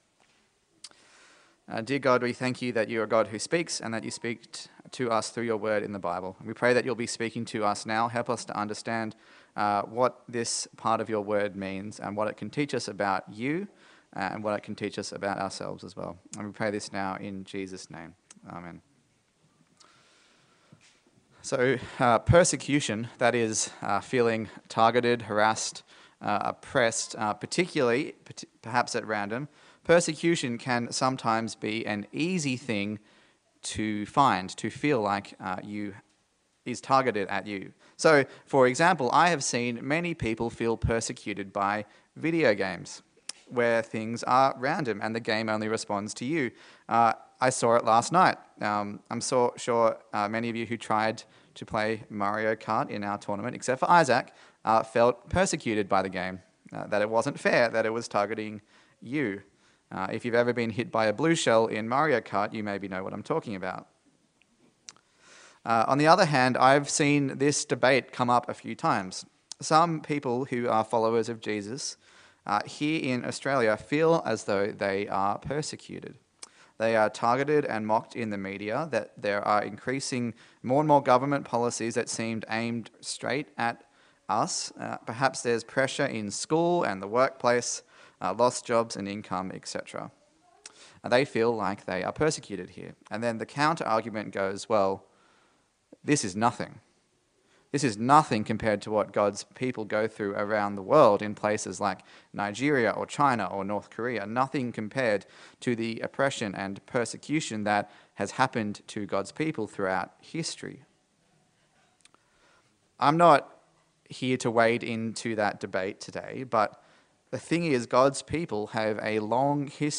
Service Type: Sunday Service A sermon in the series on the book of Esther